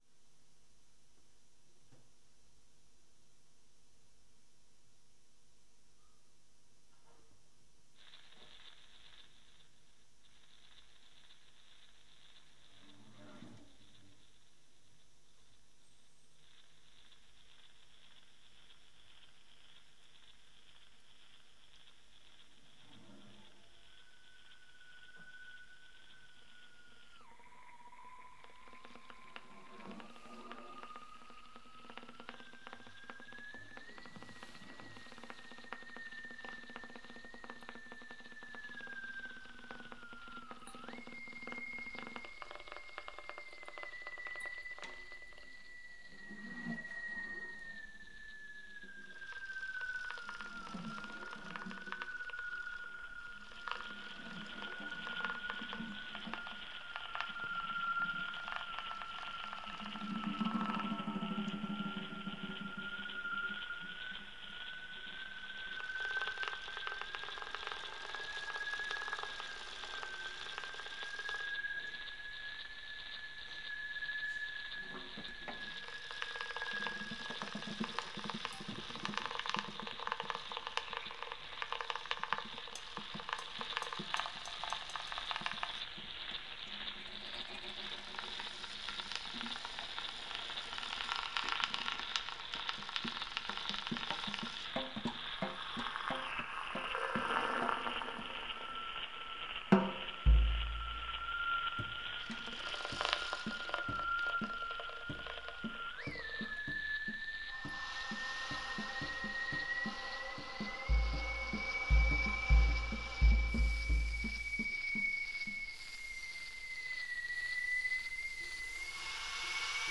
improvising musicians